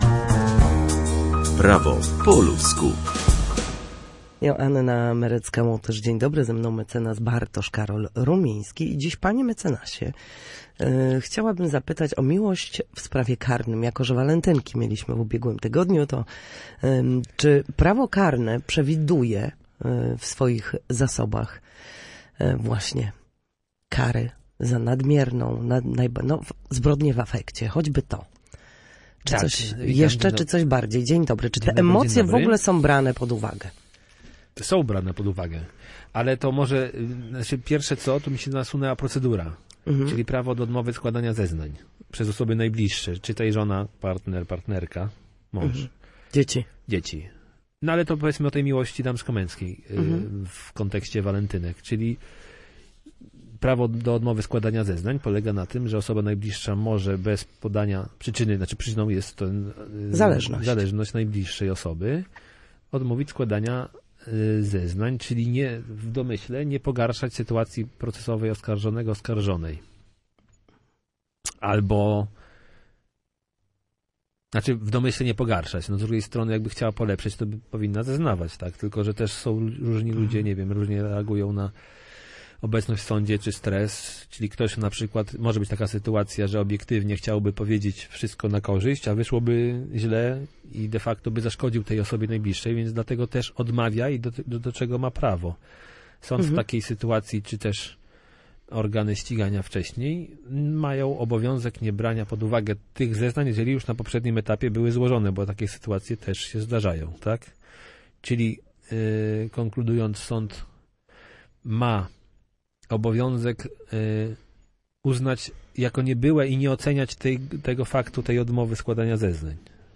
W każdy wtorek o godzinie 13:40 na antenie Studia Słupsk przybliżamy meandry prawa. W naszym cyklu gościmy ekspertów, którzy odpowiadają na jedno konkretne pytanie związane z zachowaniem w sądzie lub podstawowymi zagadnieniami prawnymi.